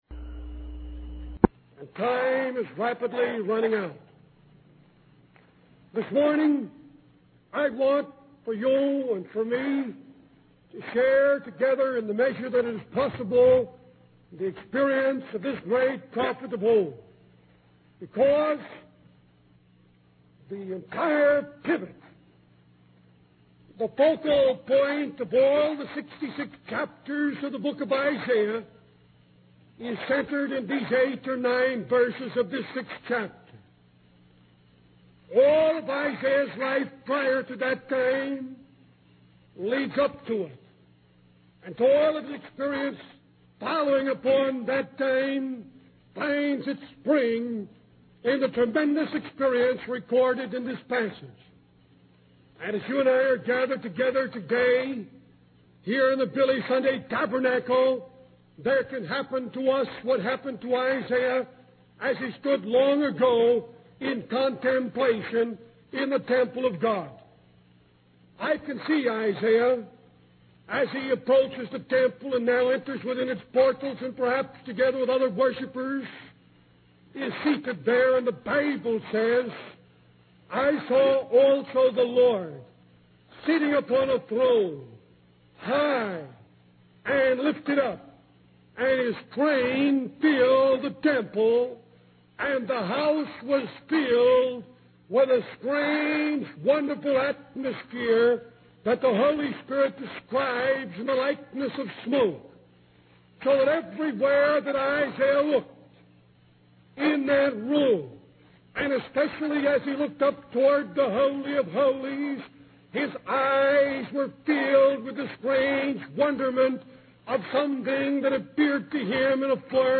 In this sermon, the speaker emphasizes that God is not concerned with talent or educational qualifications, but rather with finding individuals with loving hearts, willing spirits, and responsive attitudes.